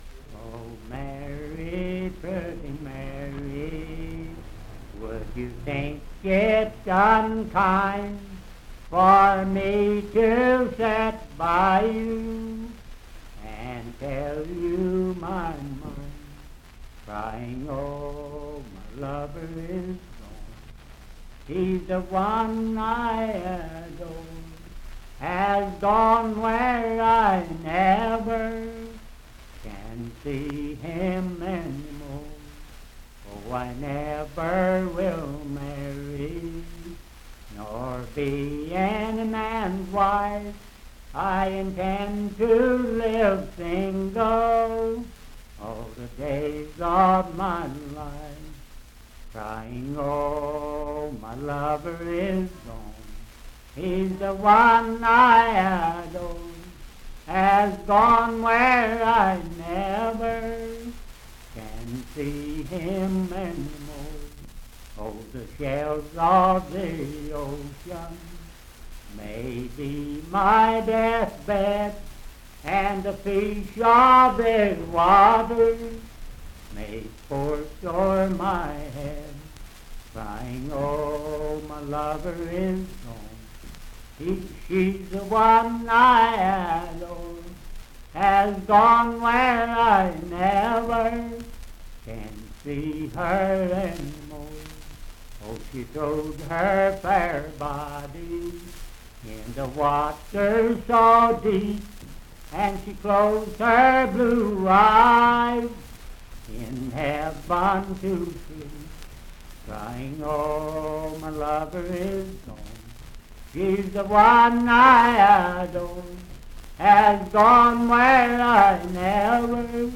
Pretty Mary - West Virginia Folk Music | WVU Libraries
Unaccompanied vocal music and folktales
Verse-refrain 4(4) & R(4).
Voice (sung)